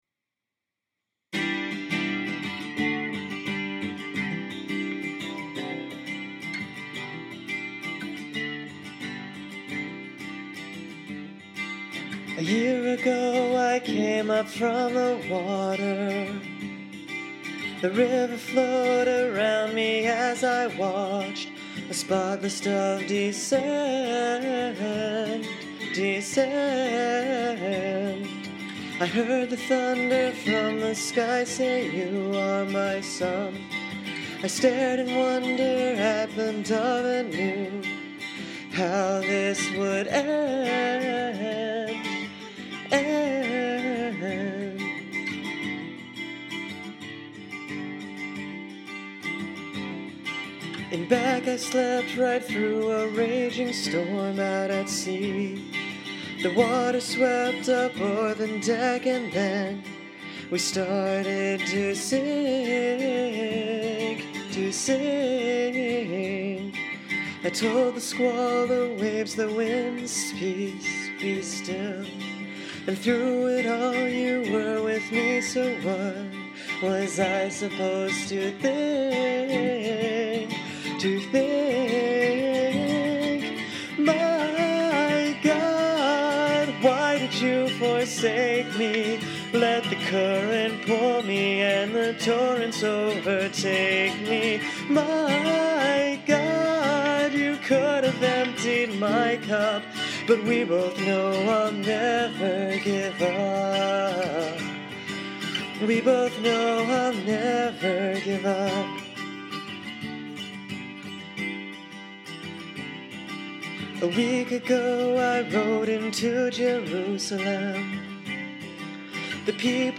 For each song, I gave myself no more than two hours to write and one hour to record it. These are by no means polished songs; they are the responses of my heart to Christ crucified.